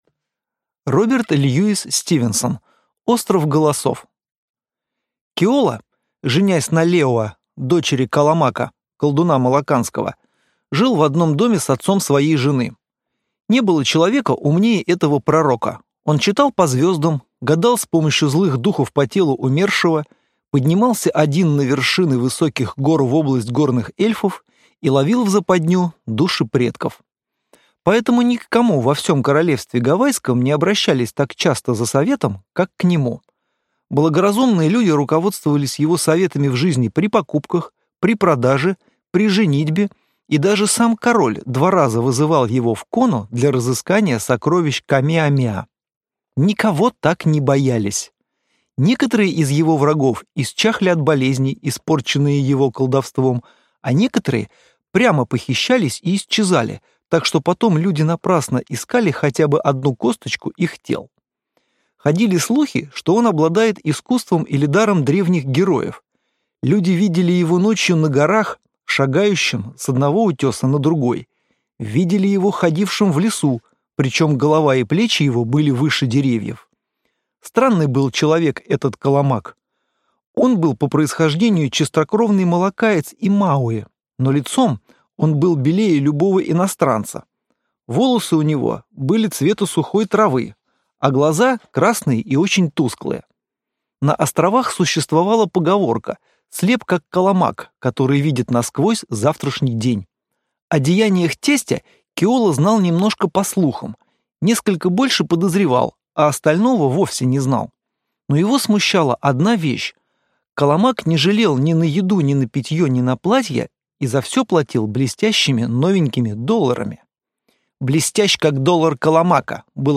Аудиокнига Остров Голосов | Библиотека аудиокниг